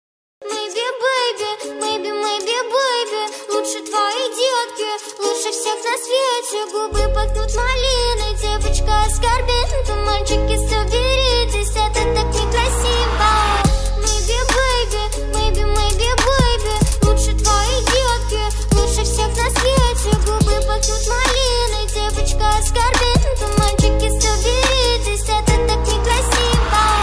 • Качество: 128, Stereo
гитара
Trap
красивый женский голос